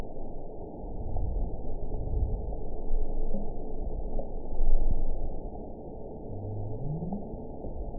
event 921692 date 12/16/24 time 22:39:22 GMT (4 months, 3 weeks ago) score 9.20 location TSS-AB03 detected by nrw target species NRW annotations +NRW Spectrogram: Frequency (kHz) vs. Time (s) audio not available .wav